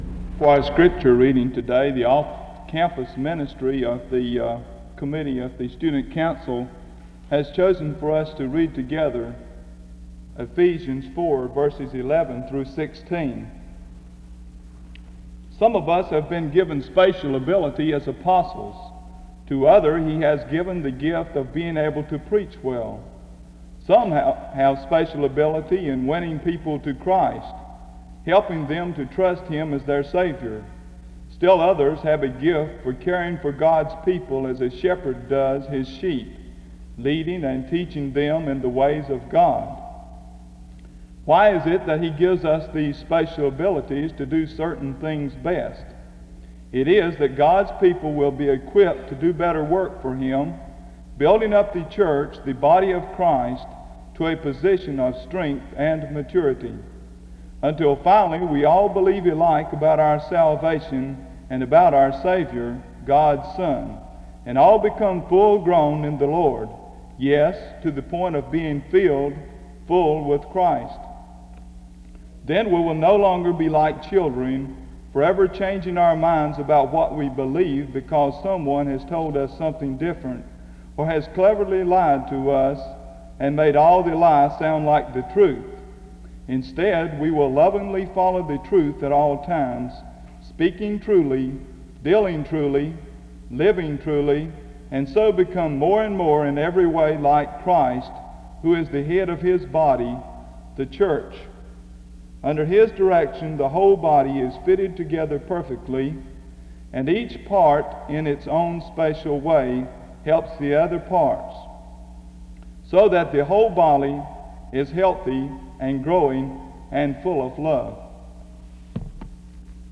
The service starts with a reading of Ephesians 4:11-16 from 0:00-2:00. An offer to join an off-campus ministry is given from 2:06-5:54. A prayer is offered from 5:55-6:41.
A representative from the Rest Home ministry speaks from 17:19-18:22.
Music plays from 21:18-24:19.